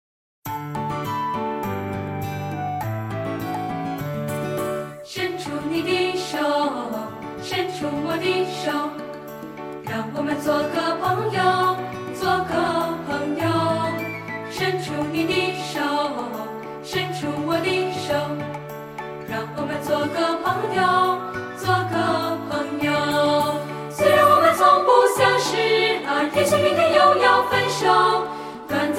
范唱：我们是朋友